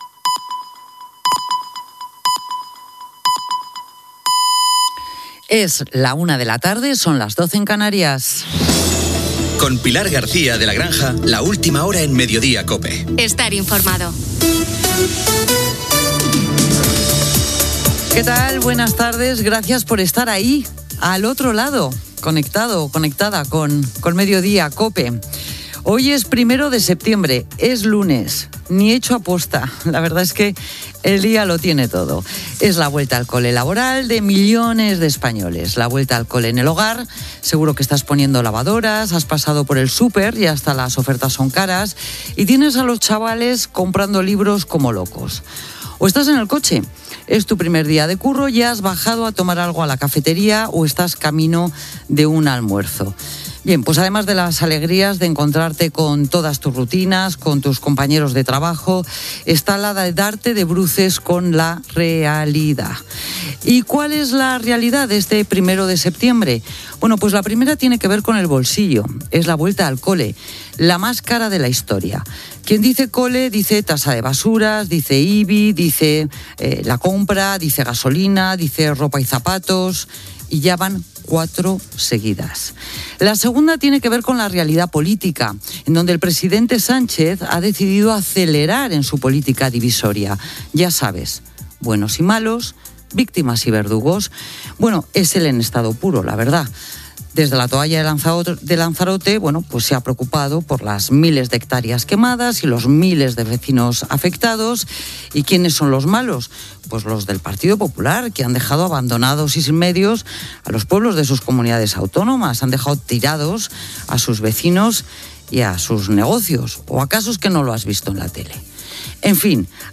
Senyals horaris. Careta del programa, inici de la temporada 2025/2026.
Info-entreteniment